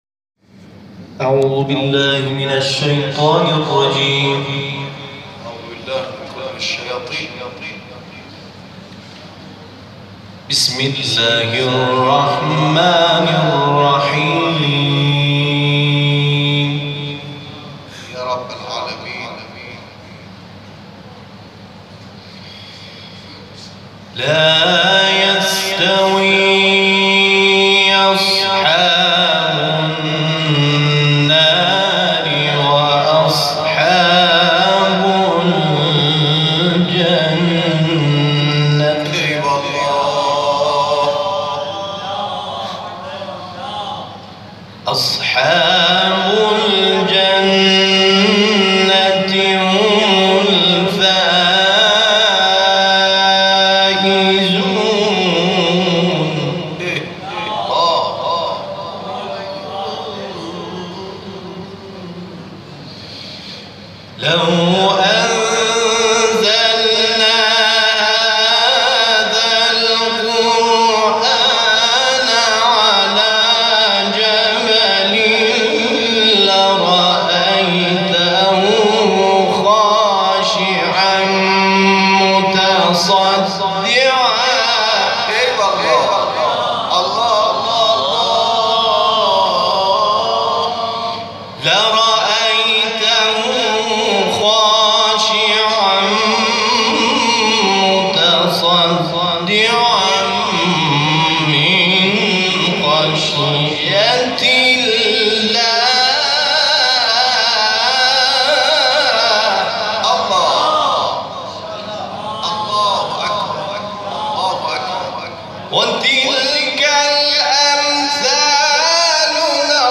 گروه فعالیت‌های قرآنی: محفل انس با قرآن کریم، شب گذشته، 25 خردادماه به مناسبت ماه مبارک رمضان در مسجد شهدای تهران برگزار شد.
تلاوت